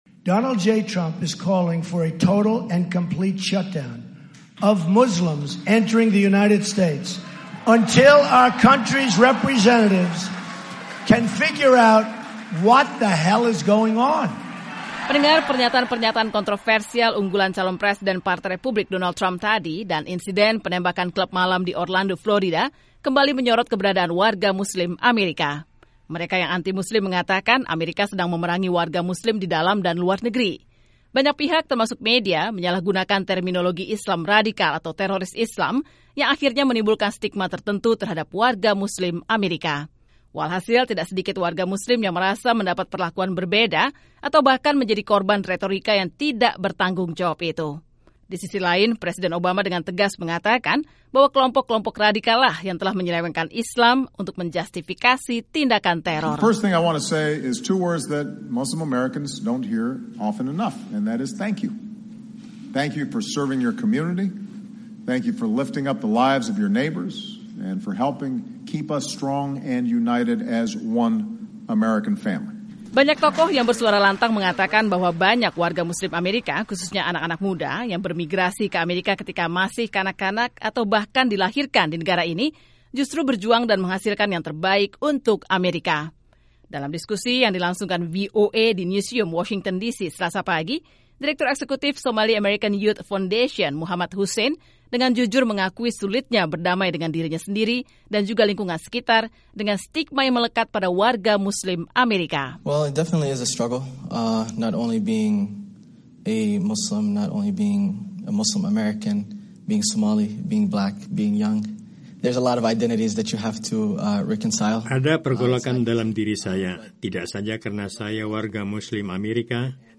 Diskusi VOA yang berlangsung di Newseum ini juga menyentuh banyak isu lain seperti soal upaya mencegah radikalisasi lewat internet, perubahan sikap pasca insiden penembakan atau munculnya pernyataan kontroversial, transisi menuju budaya Barat, partisipasi warga Muslim-Amerika dalam pemilu dan lain-lain.